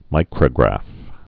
(mīkrə-grăf)